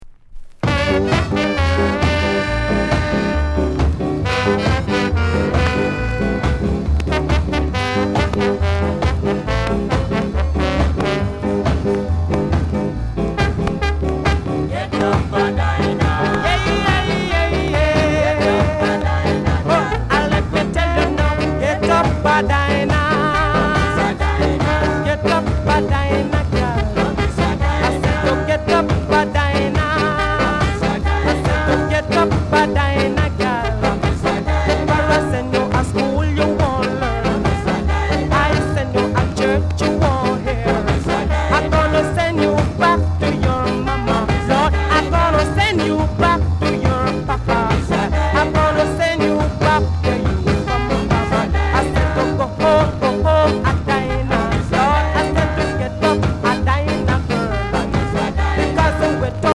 NICE SKA INST